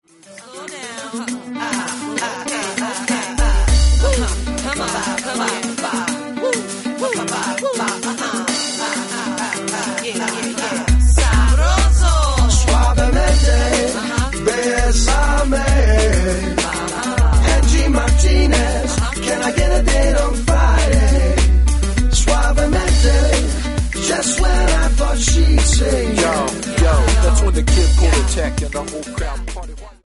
MP3 – Original Key – Backing Vocals Like Original